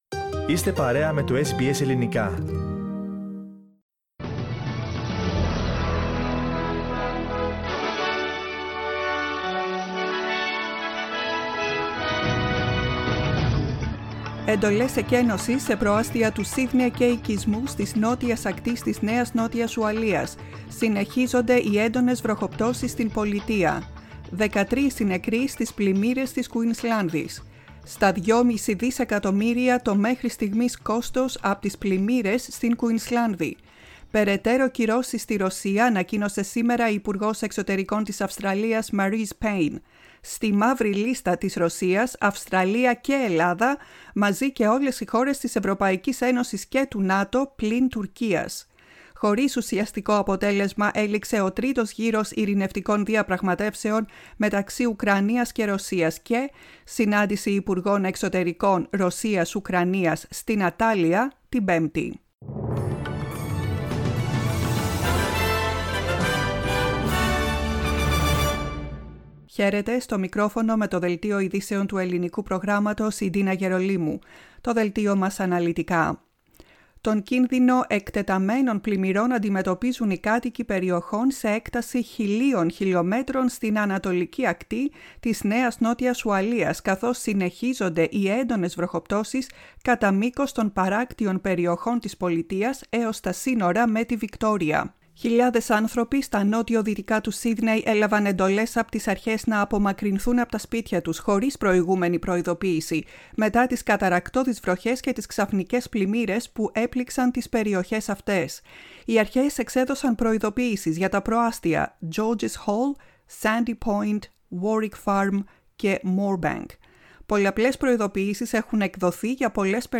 Listen to the main bulletin of the day from the Greek Program.